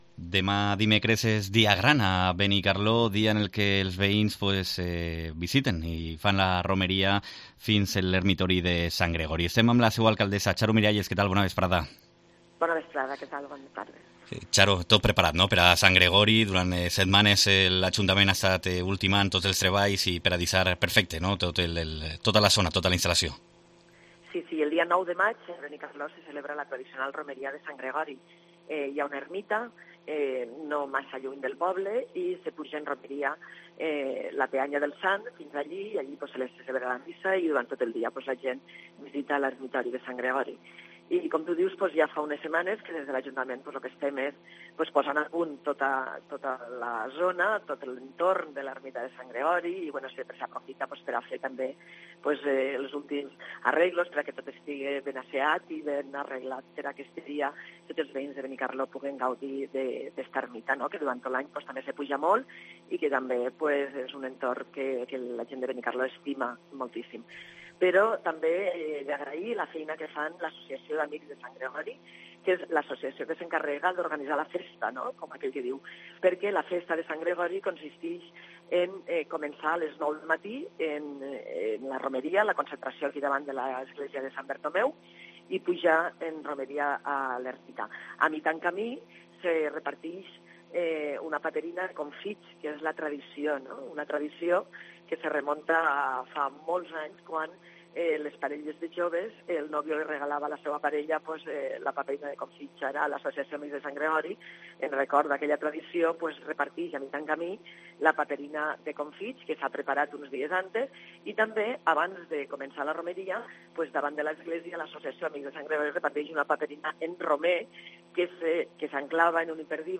Entrevista a l'alcaldesa, Xaro Miralles